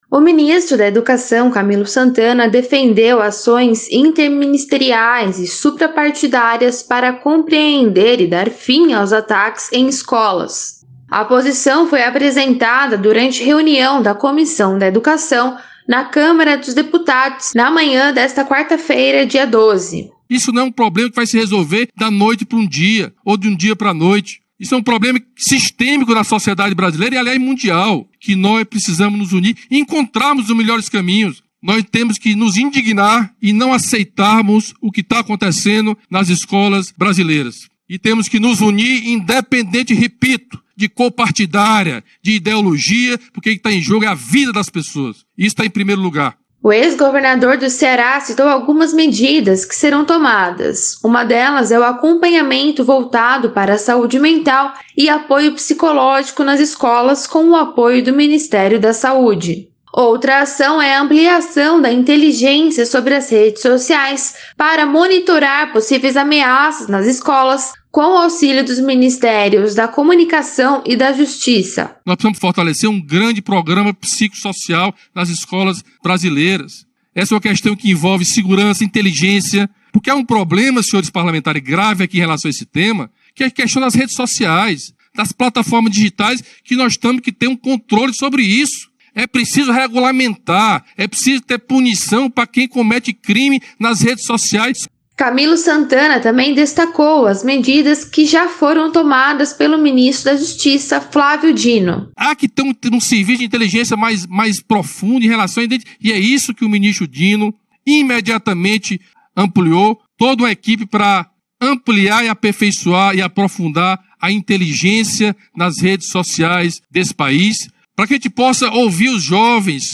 O ministro da Educação, Camilo Santana, defendeu ações interministeriais e suprapartidárias para compreender e dar fim aos ataques em escolas, durante reunião da Comissão da Educação na Câmara dos Deputados, na manhã desta quarta-feira (12).